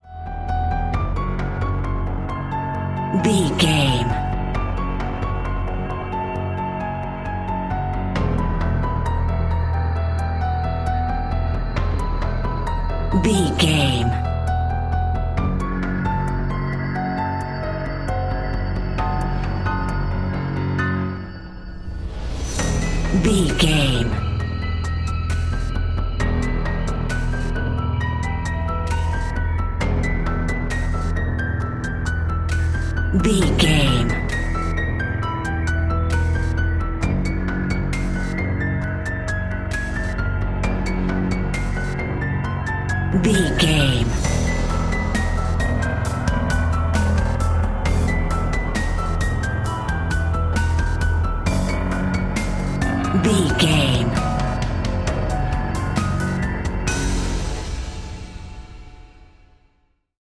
Survival horror
Aeolian/Minor
eerie
ominous
drum machine
synthesiser